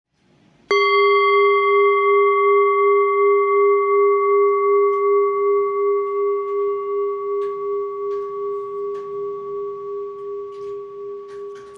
Jambati Singing Bowl Singing Bowl, Buddhist Hand Beaten, Antique Finishing, Jhumkabati, Select Accessories
Material Seven Bronze Metal
It's planned in a U-bended shape and has a thick edge.
It can discharge an exceptionally low dependable tone.